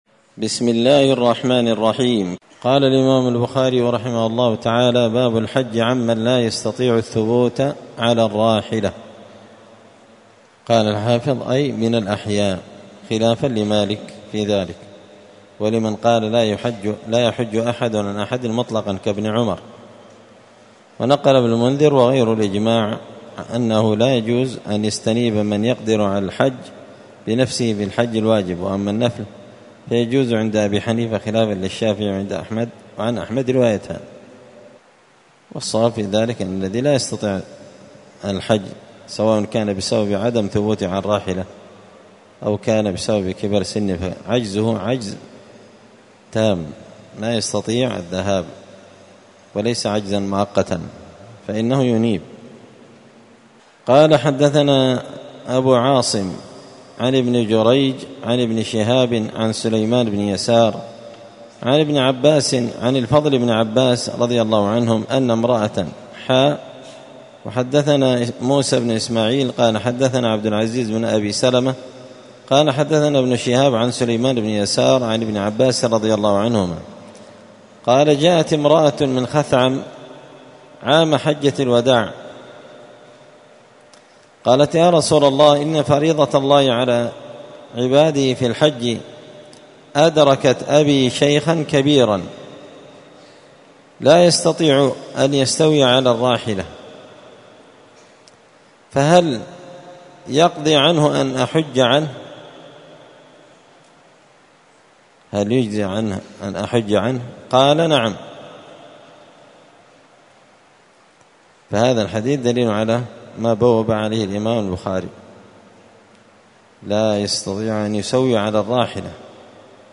مسجد الفرقان قشن المهرة اليمن 📌الدروس اليومية